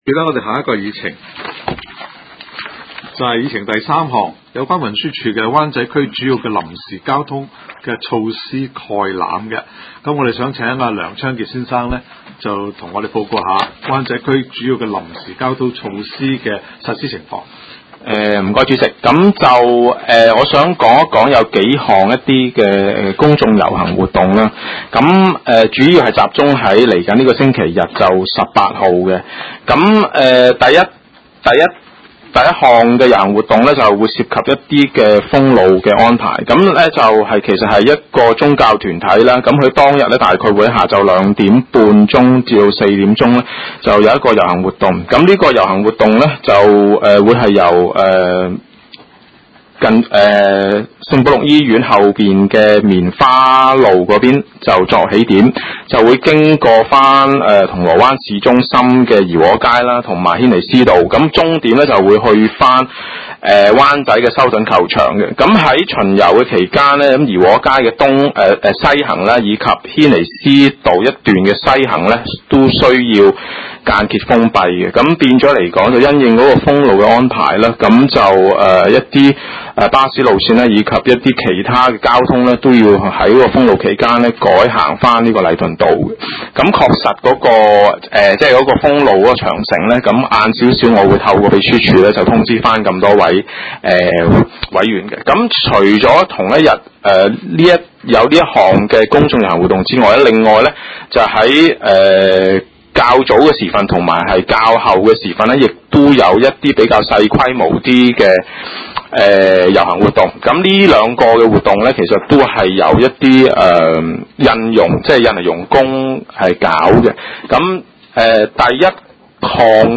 發展、規劃及交通委員會第十二次會議
灣仔民政事務處區議會會議室